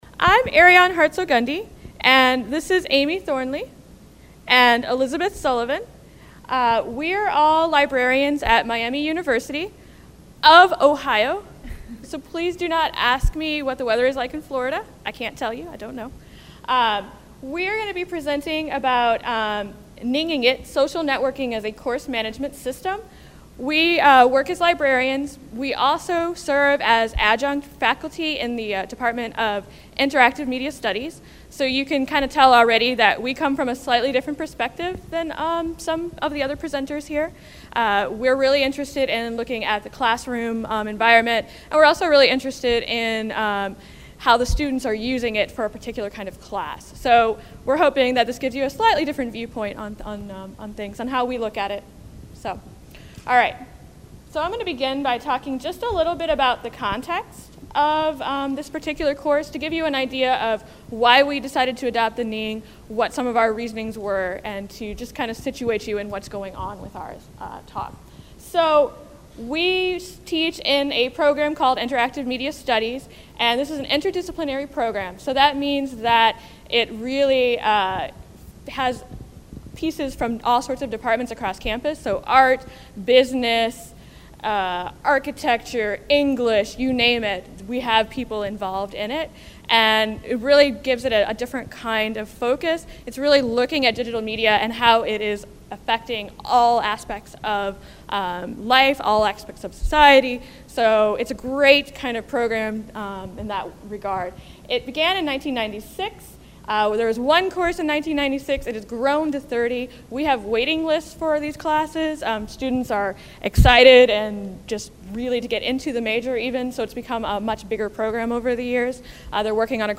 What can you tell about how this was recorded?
Continental Ballroom, Mezzanine Level